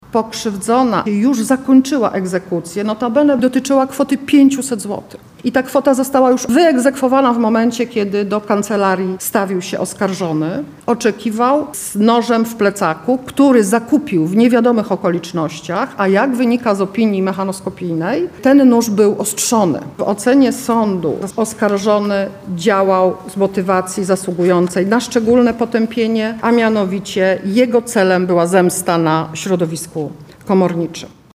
– Oskarżony przyszedł do kancelarii, bo rzekomo chciał zawrzeć ugodę do której ostatecznie nie doszło – mówiła podczas uzasadnienia wyroku, Sędzia Sądu Apelacyjnego w Lublinie – Beata Siewielec.